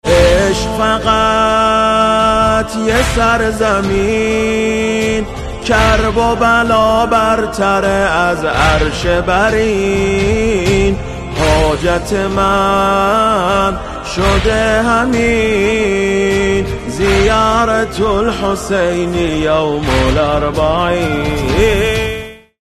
زنگ موبایل (باکلام) حماسی و محزون